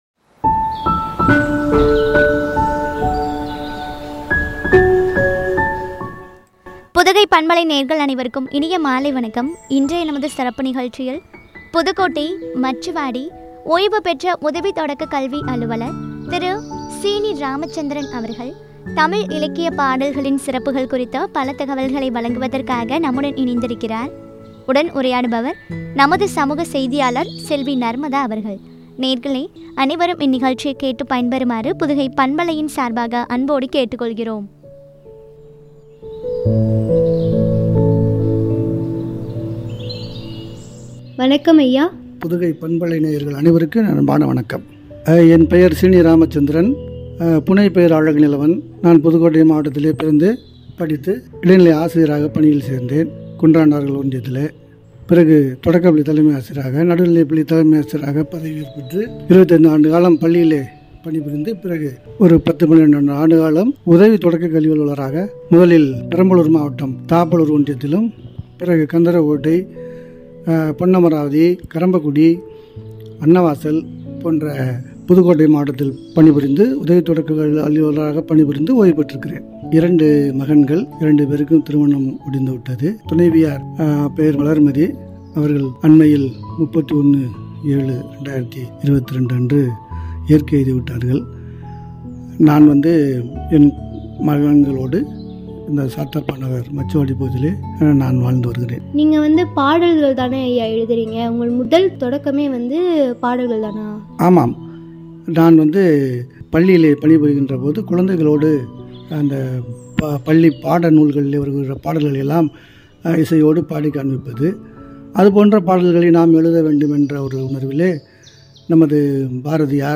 தமிழ் இலக்கிய பாடல்களின் சிறப்புகள் பற்றிய உரையாடல்.